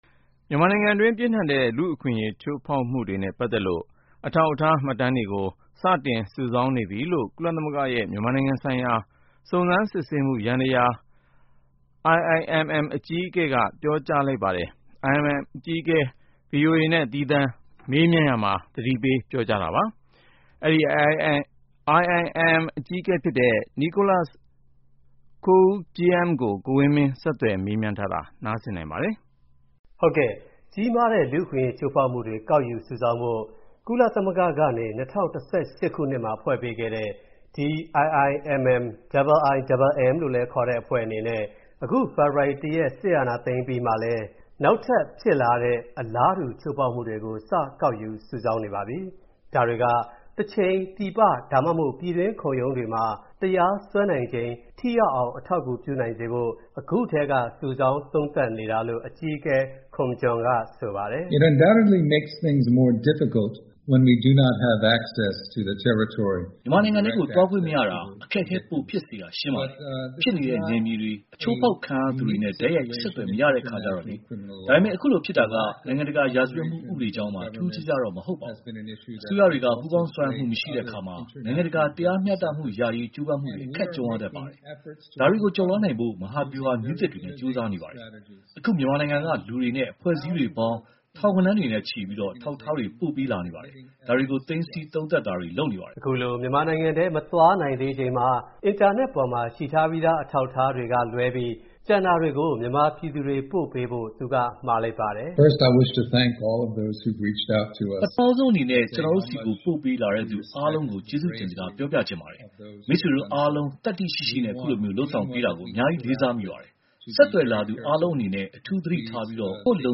စဈတပျက အာဏာသိမျးလိုကျတာကို ဆန့ျကငြျလှုပျရှားသူတှအေပေါျ သကြေထေိခိုကျတဲ့အထိ နှိမျနငျးနတေဲ့ လုံခွုံရေးတပျဖှဲ့တှေ အနနေဲ့လညျး အမိန့ျပေးလို့ လုပျရပါတယျဆိုပွီး ဆငျခွပေေးလို့ မရနိုငျကွောငျး IIMM အကွီးအကဲက ဗှီအိုအနေဲ့ သီးသန့ျမေးမွနျးရာမှာ သတိပေးလိုကျပါတယျ။